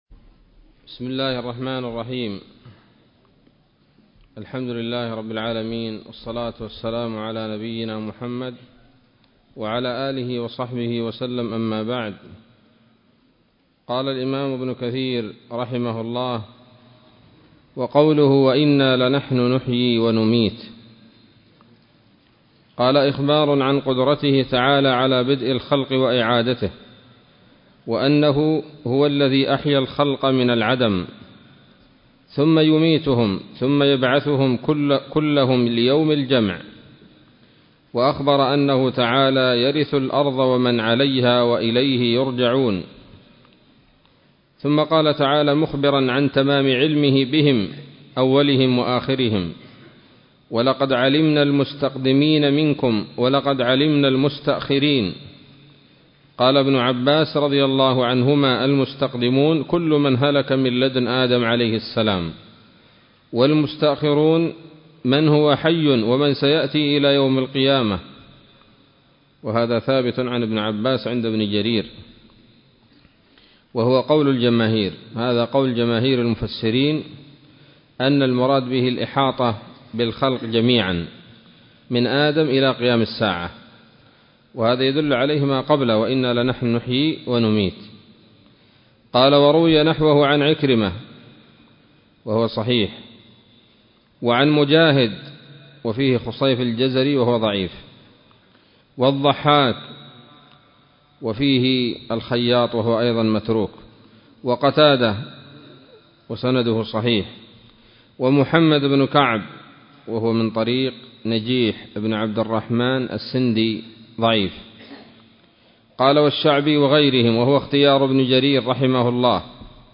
الدرس الرابع من سورة الحجر من تفسير ابن كثير رحمه الله تعالى